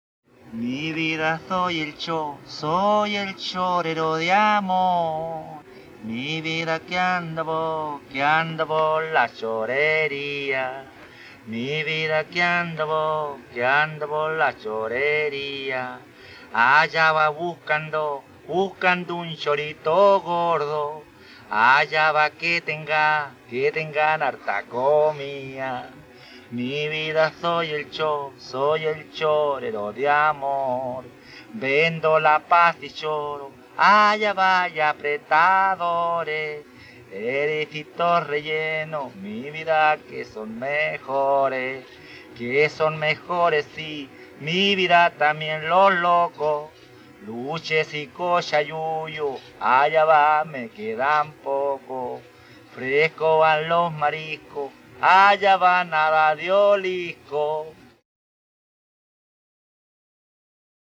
Cueca interpretada a capela
Música tradicional
Folklore
Cueca